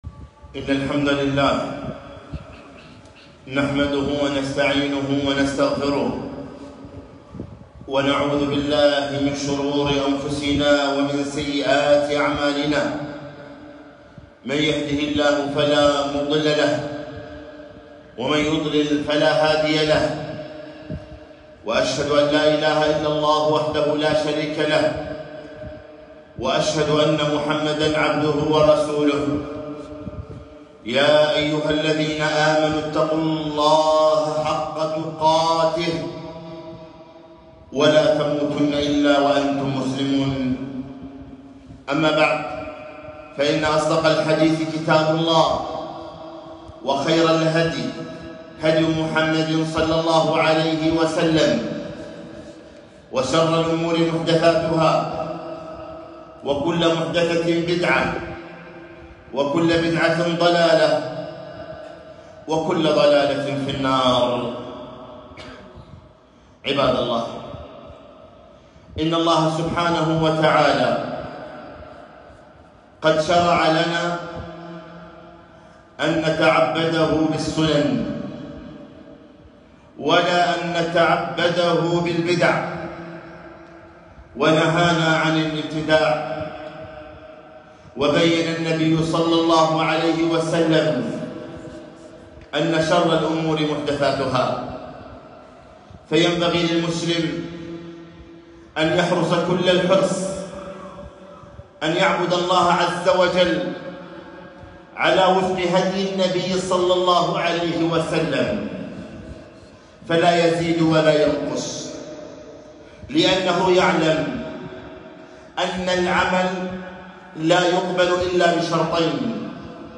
خطبة - النصف من شعبان بين الاتباع والابتداع